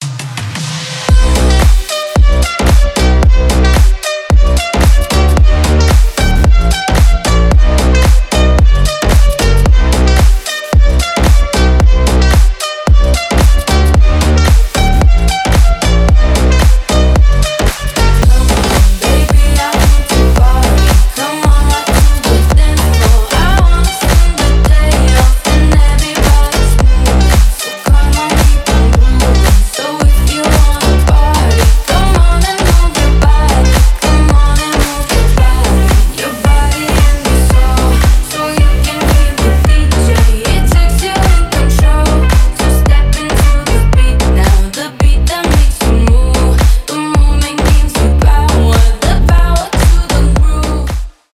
club house